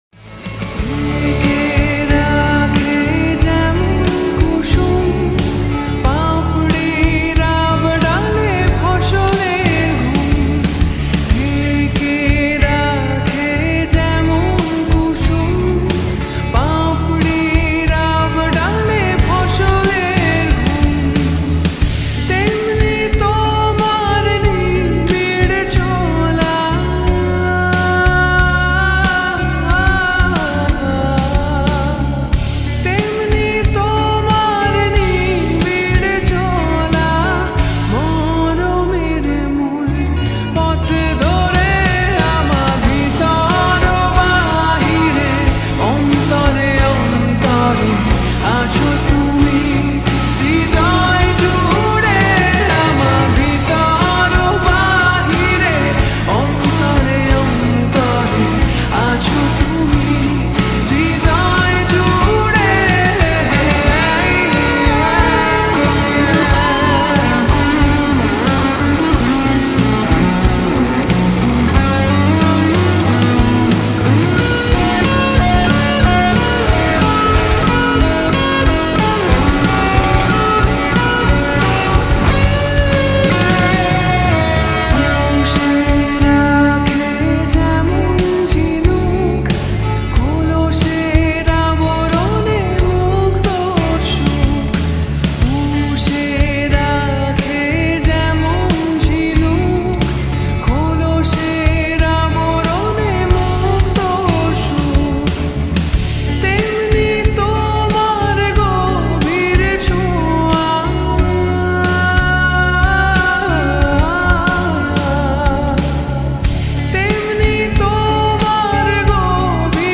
Slowed And Reverb Bangla New Lofi Song